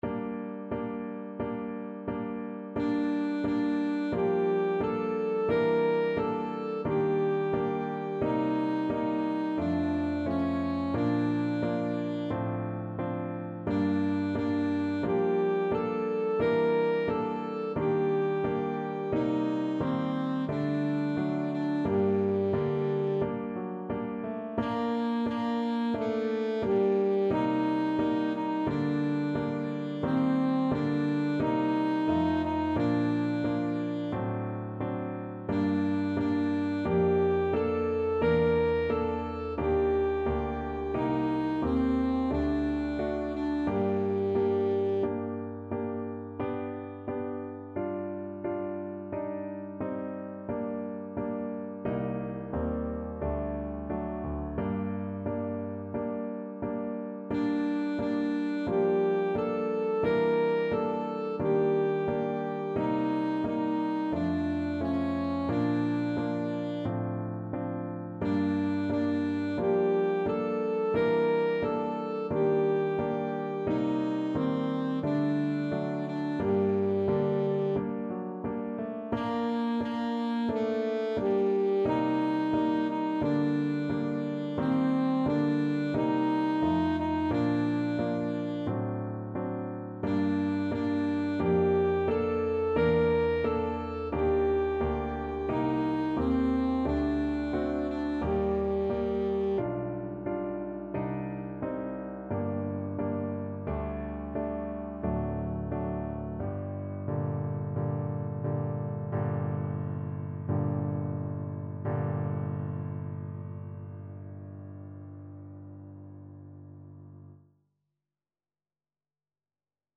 Alto Saxophone
G minor (Sounding Pitch) E minor (Alto Saxophone in Eb) (View more G minor Music for Saxophone )
4/4 (View more 4/4 Music)
Andante =c.88
G4-Bb5
Classical (View more Classical Saxophone Music)
Japanese
kojo_no_tsuki_ASAX.mp3